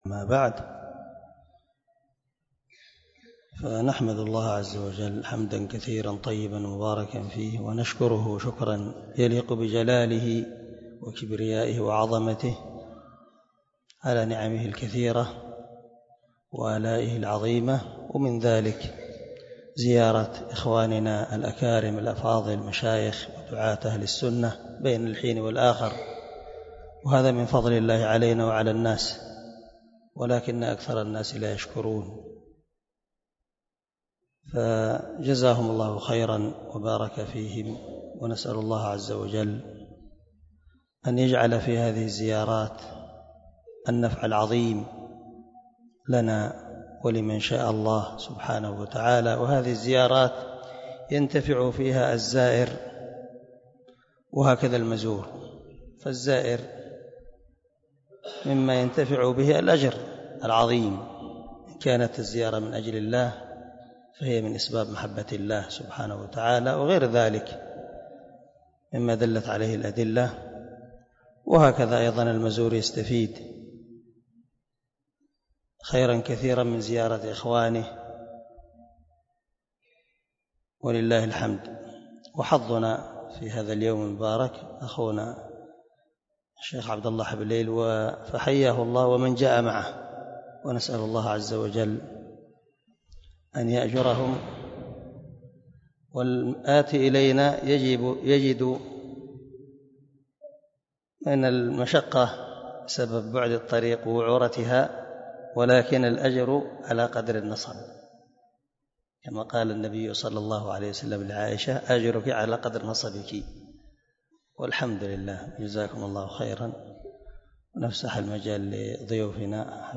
كلمة ترحيب